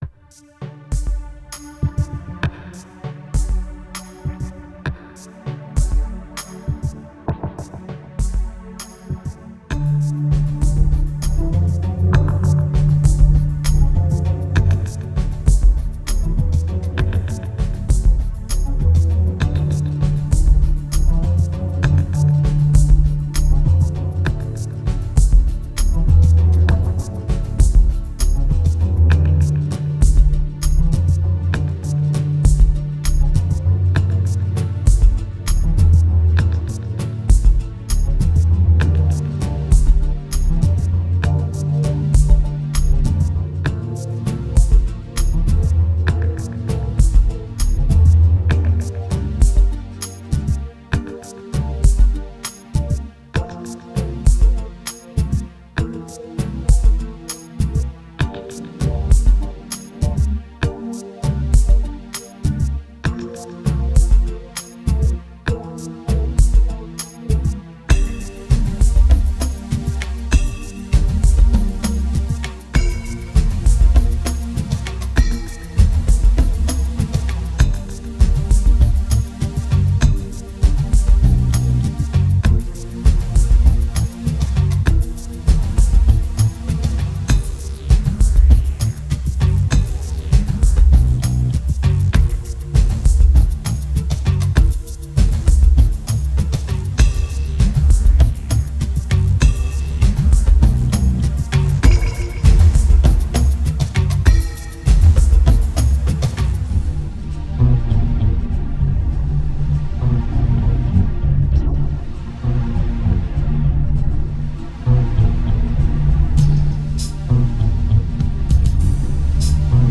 Tense and diverse